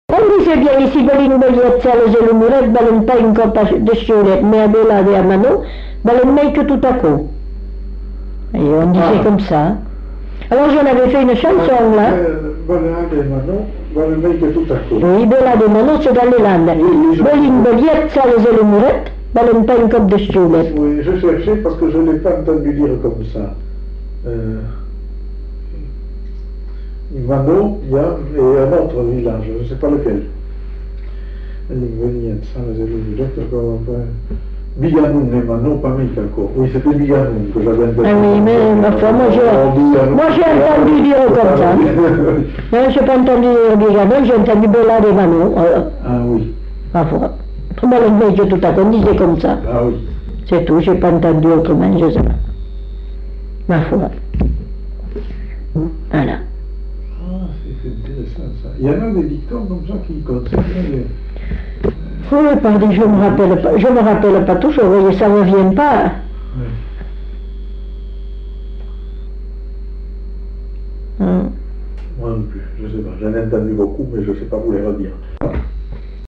Genre : forme brève
Effectif : 1
Type de voix : voix de femme
Production du son : récité
Classification : blason populaire